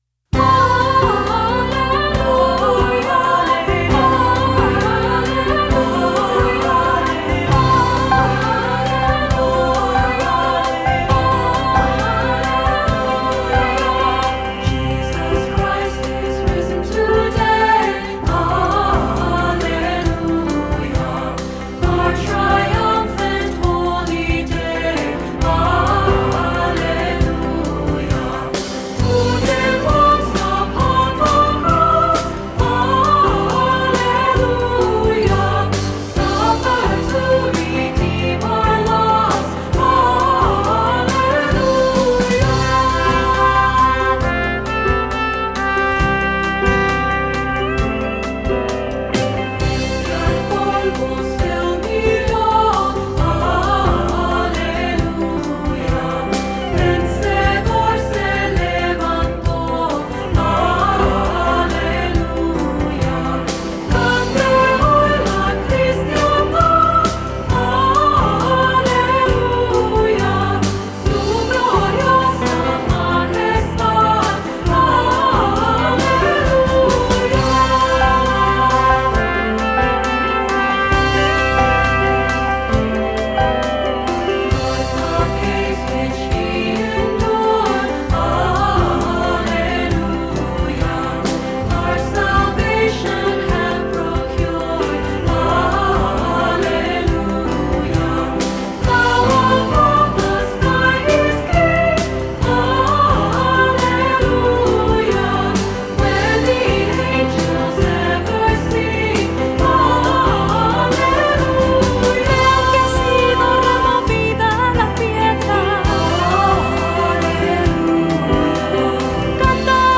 Voicing: Three-part mixed; Cantor; Assembly